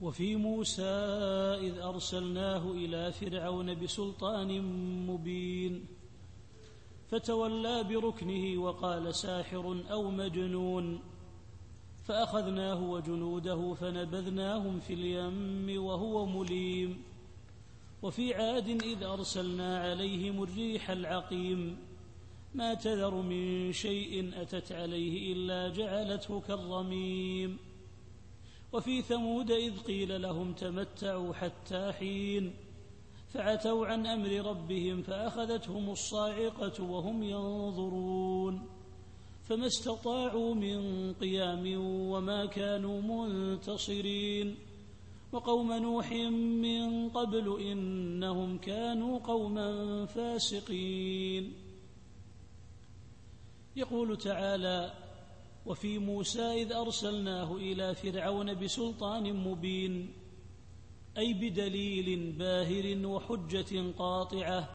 التفسير الصوتي [الذاريات / 38]